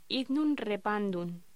Locución: Hydnum repandum